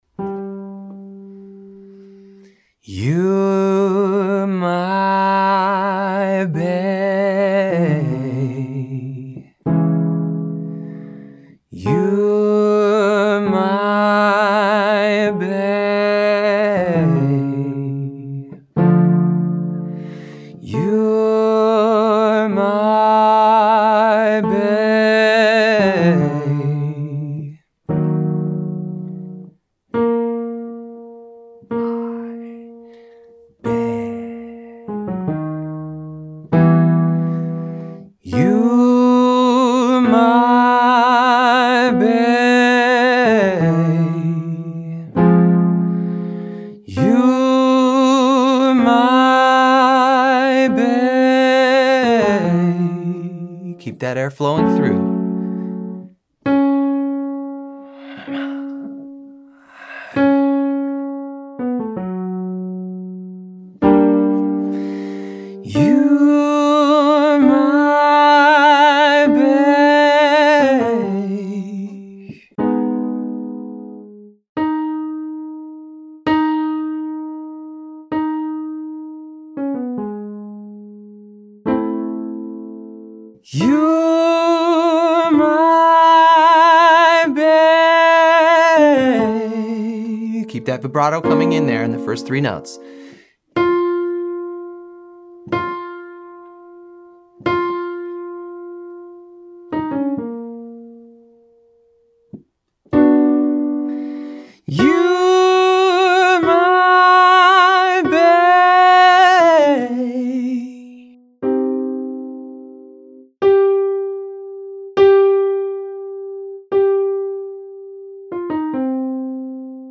Relaxed Riffs/Runs
Let’s polish these runs while keeping a rich tone. Start each phrase by accessing some vibrato on the sustained notes.